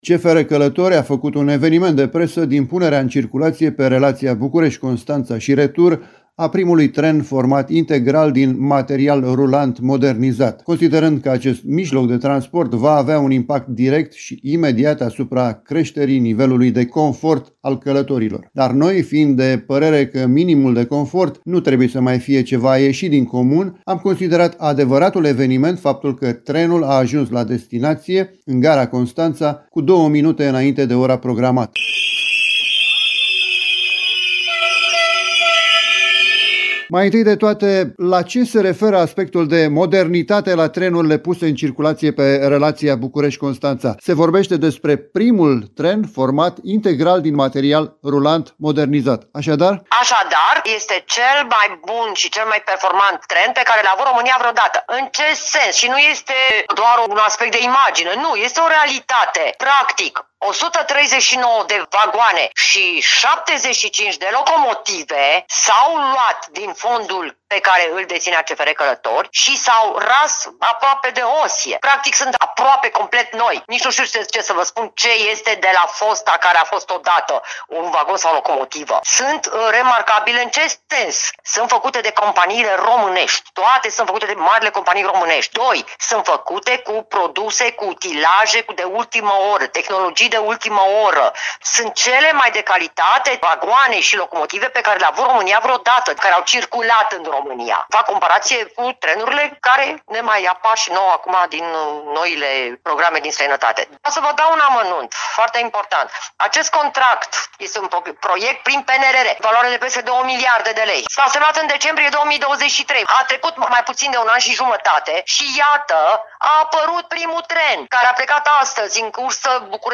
Obiectivele proiectului prin care în perioada următoare vor fi puse în circulație astfel de trenuri vizează, în principal, îmbunătățirea confortului pasagerilor, creșterea eficienței, accesibilității și gradului de digitalizare a transportului feroviar de călători. Un material pe această temă realizat de